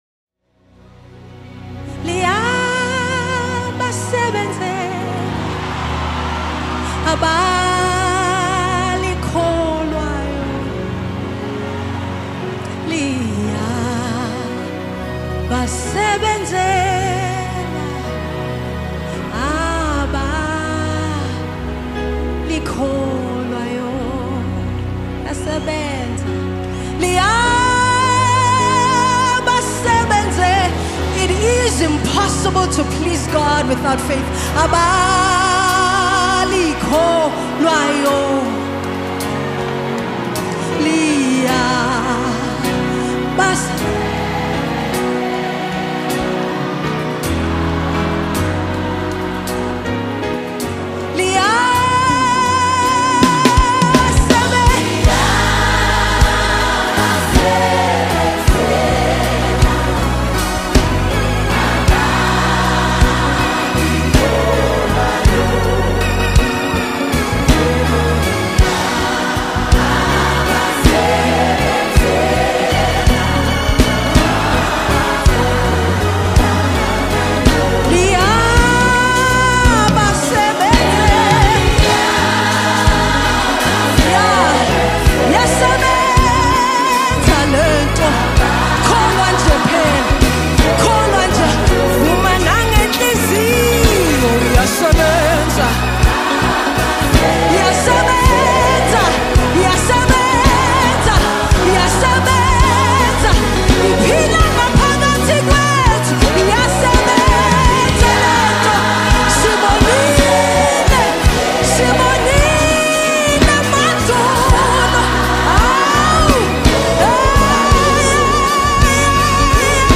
South African Gospel
Genre: Gospel/Christian